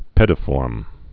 (pĕdə-fôrm)